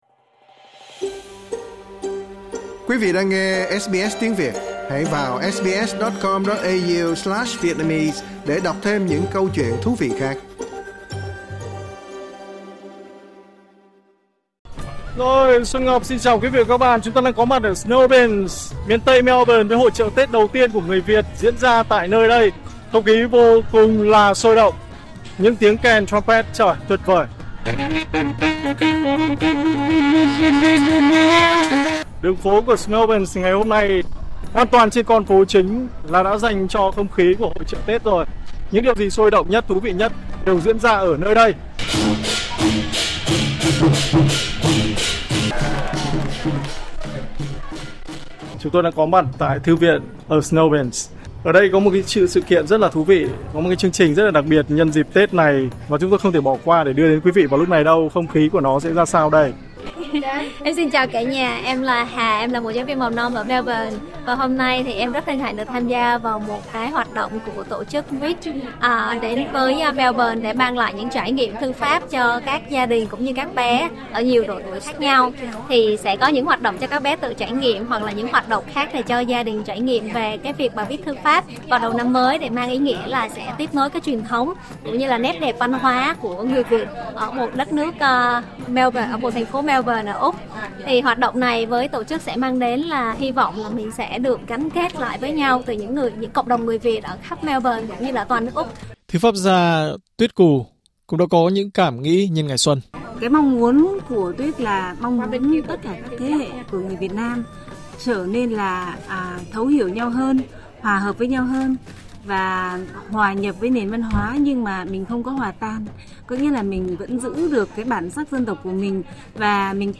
Dưới cái nắng vàng rực rỡ của mùa hè nước Úc, không khí Tết Việt vẫn bùng nổ đầy lôi cuốn tại St Albans, Melbourne. Hội chợ Tết Bính Ngọ 2026 không chỉ là không gian rộn ràng của tiếng trống hội và sắc hoa mai đào, mà còn là hành trình tìm về cội nguồn đầy xúc động.